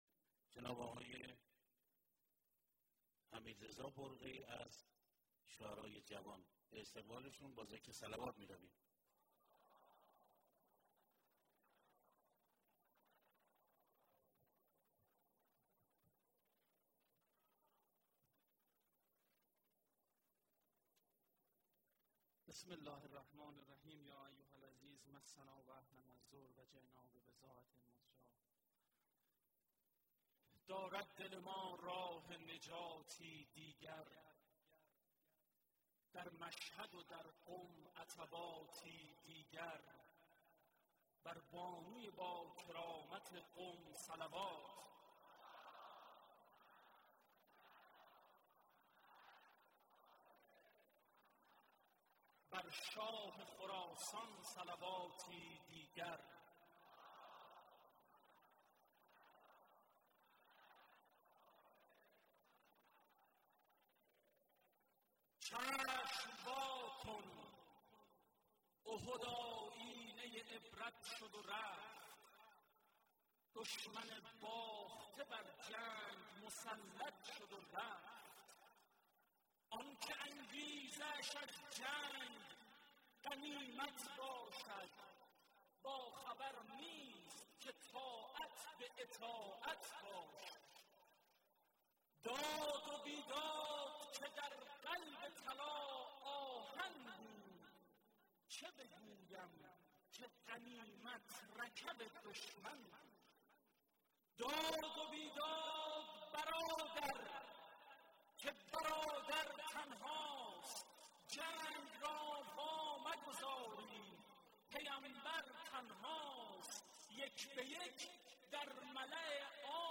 ولادت حضرت زهرا(س) 1392/ حسینیه امام خمینی(ره)
مداحی